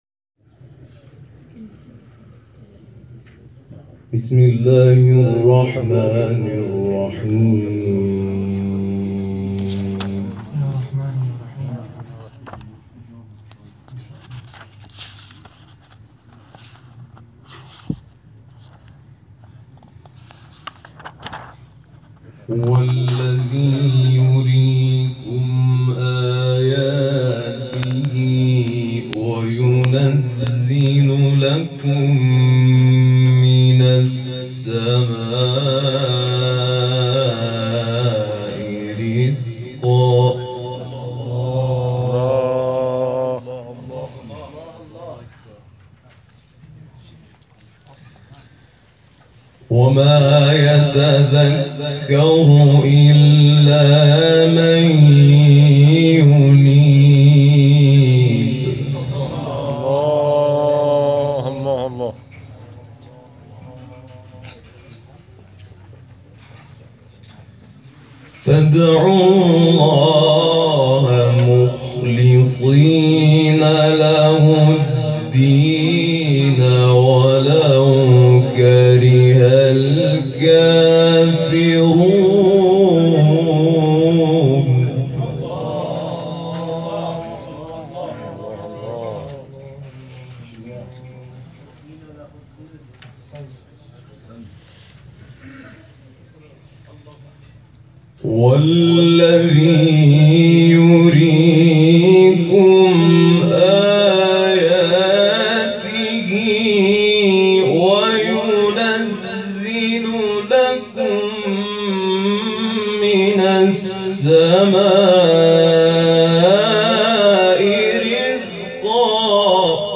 گروه جلسات و محافل ــ آخرین جلسه دارالقرآن امامزاده محمد هلال بن علی(ع) در سال ۹۶ با حضور قاریان و حافظان ممتاز کشوری شهرستان‌های کاشان و آران و بیدگل برگزار شد.